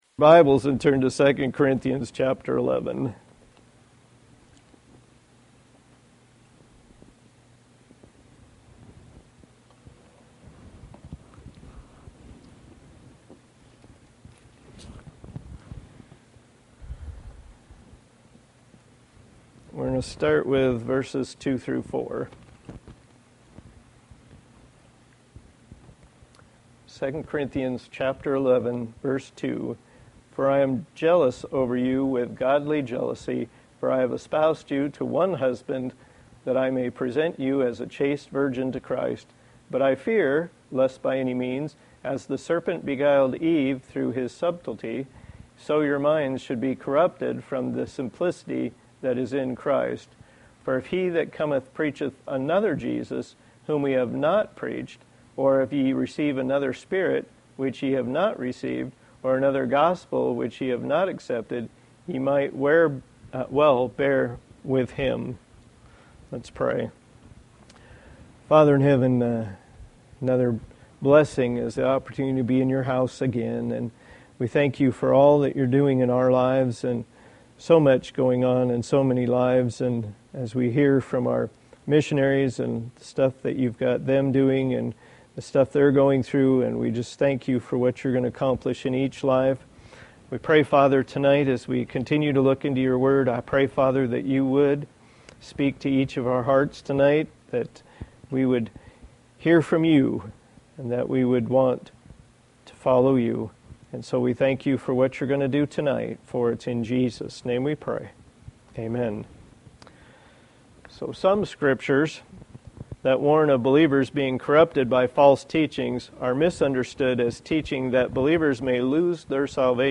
Service Type: Thursday Evening